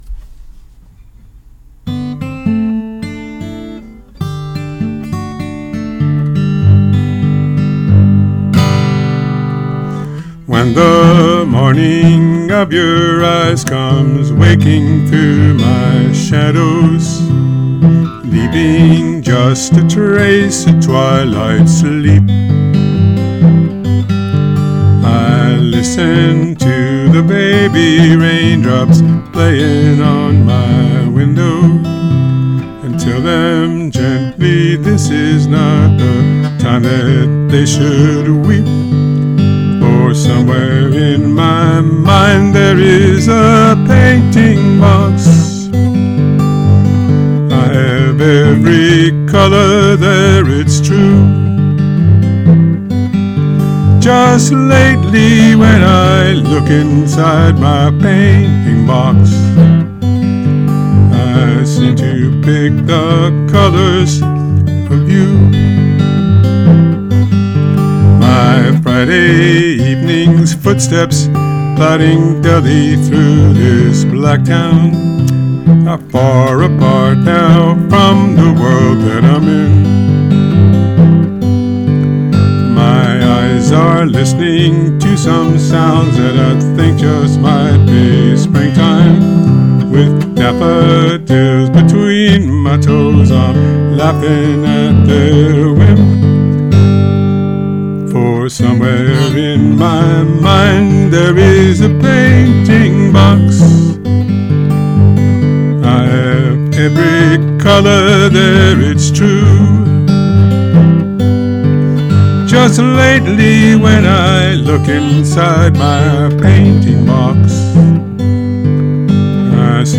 lilting, whimsical